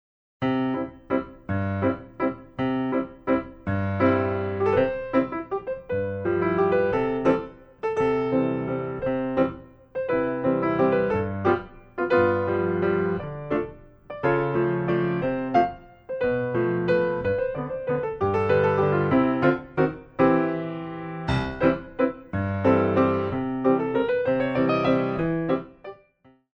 Big Waltz